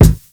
Kicks
JayDeeKick6.wav